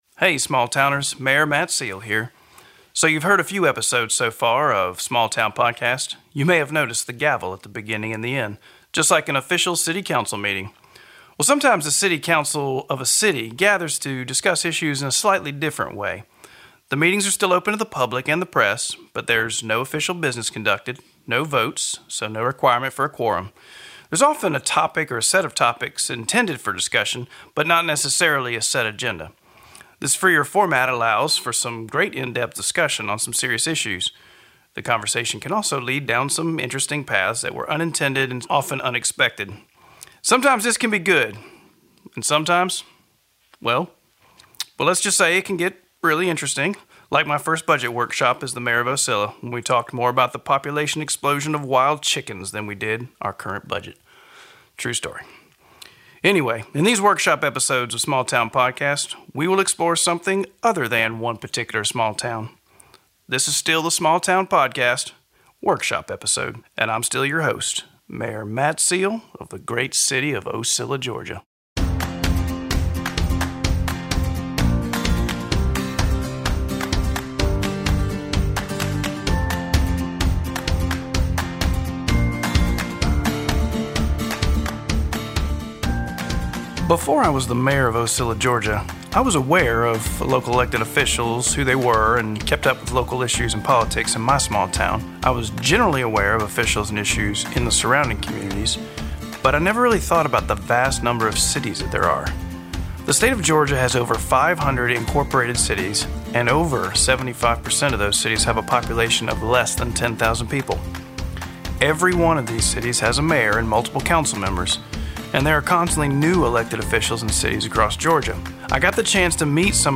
Ocilla Mayor and Small Town Podcast Host Matt Seale travels to the Mayor’s Day Conference in this first ever Workshop Episode of the podcast.
Mayor Matt then talks with several newly elected small town Mayors and Councilmembers to see why they decided to run and what they hope to accomplish now that they’ve been elected.